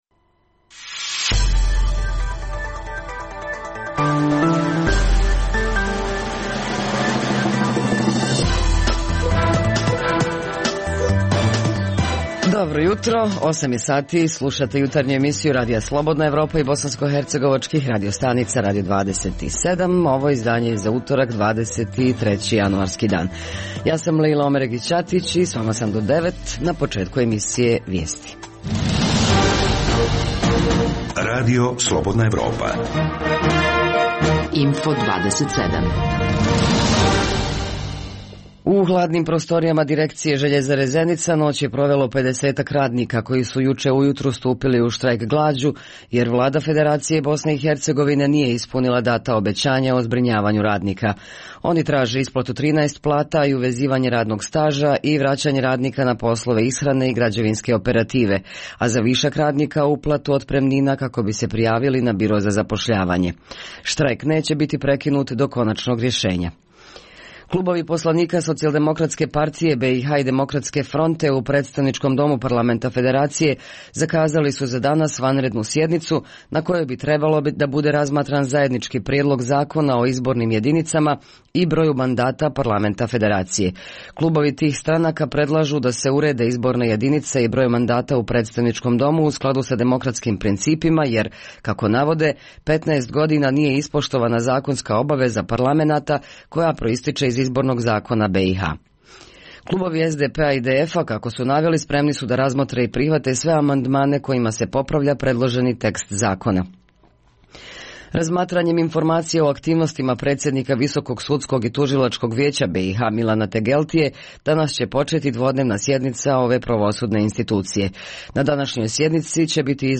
Poslušajte priče naših dopisnika iz Prijedora, Doboja, Brčkog i Jablanice.